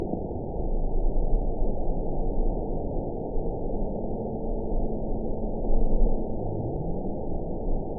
event 922689 date 03/10/25 time 08:05:23 GMT (3 months, 1 week ago) score 9.57 location TSS-AB10 detected by nrw target species NRW annotations +NRW Spectrogram: Frequency (kHz) vs. Time (s) audio not available .wav